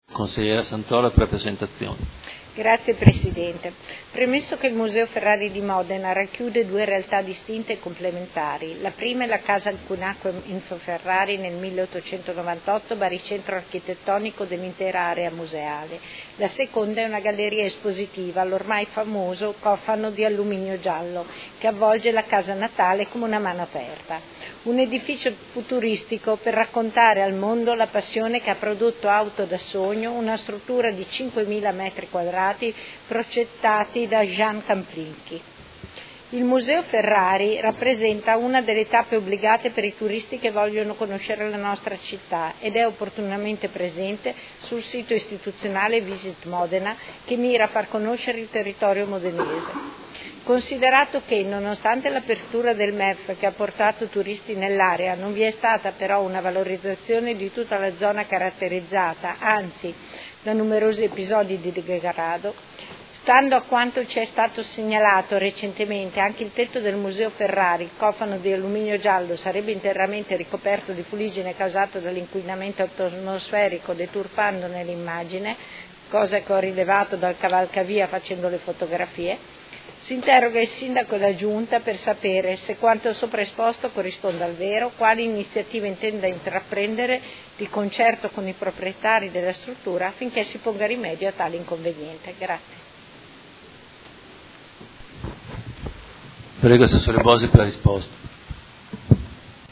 Seduta del 12/12/2019. Interrogazione della Consigliera Santoro (Lega Modena) avente per oggetto: Museo Enzo Ferrari